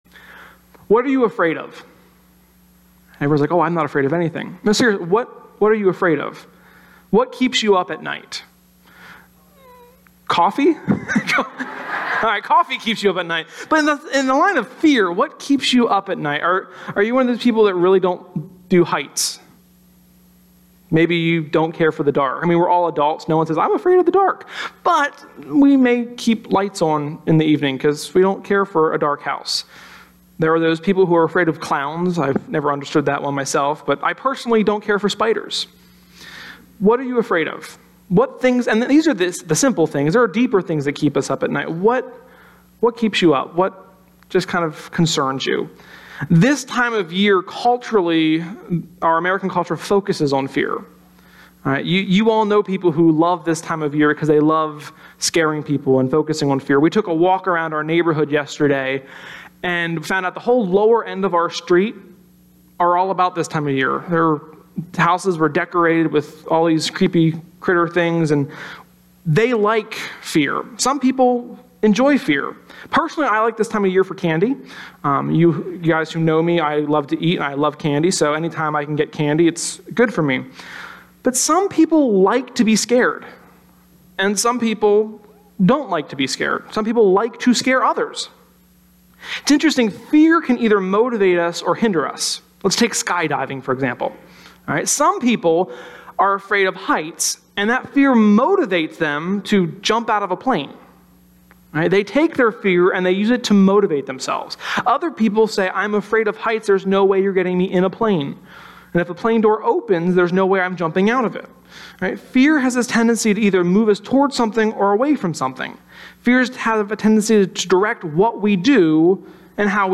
Sermon-10.8.17.mp3